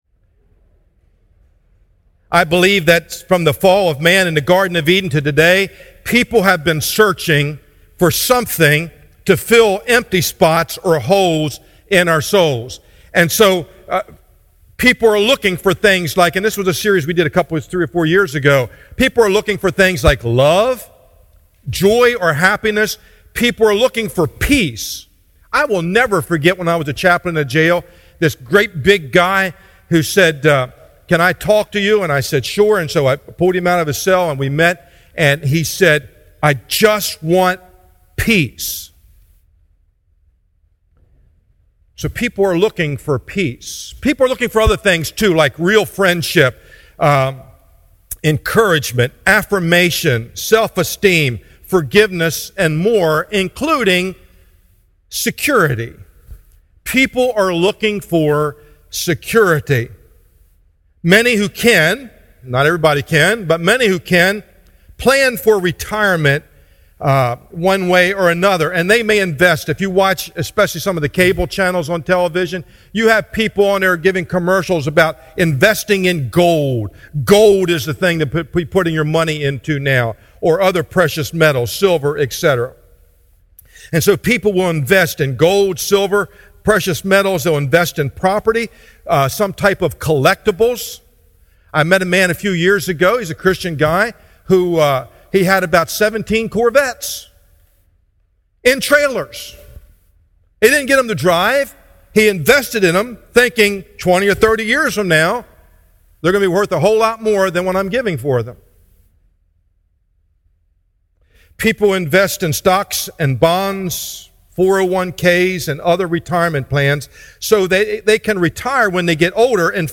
sermon text: Matthew 7:24-29